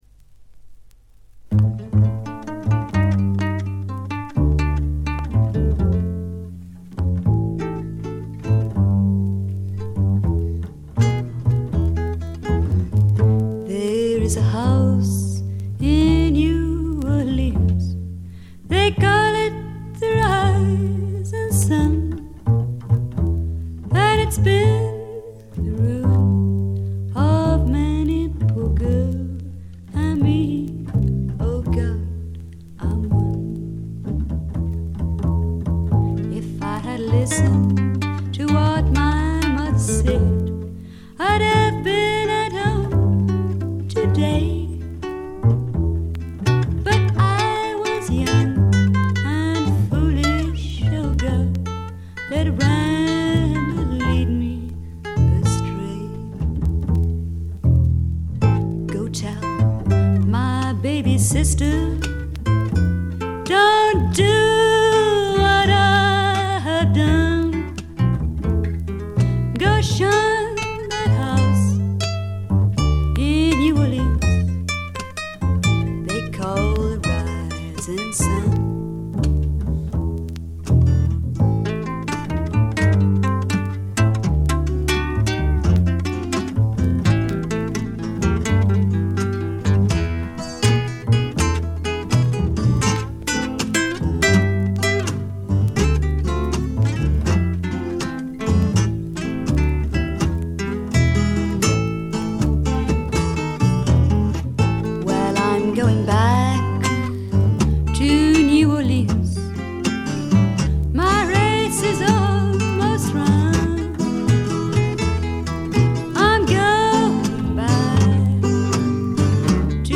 軽微なバックグラウンドノイズ程度。
英国フィメール・フォークの大名作でもあります。
内容はというとほとんどがトラディショナル・ソングで、シンプルなアレンジに乗せた初々しい少女の息遣いがたまらない逸品です。
モノラル盤です。
試聴曲は現品からの取り込み音源です。